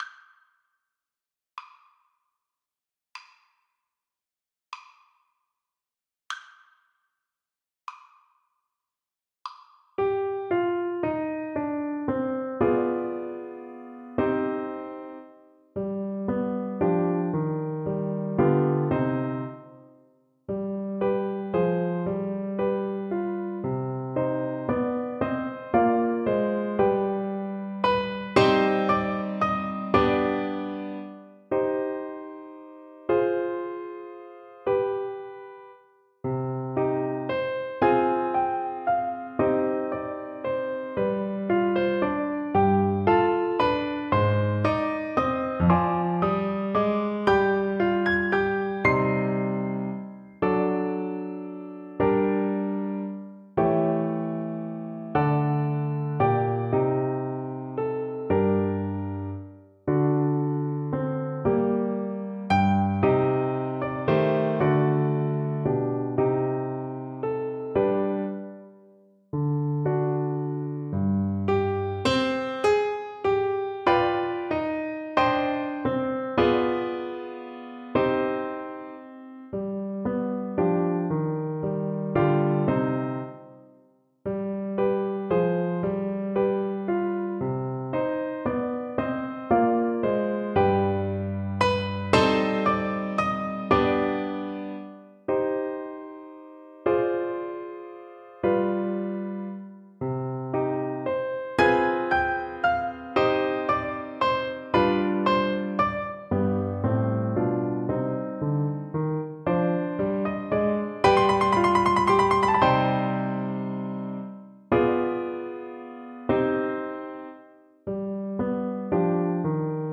Allegretto con moto .=56
12/8 (View more 12/8 Music)
Classical (View more Classical Clarinet Music)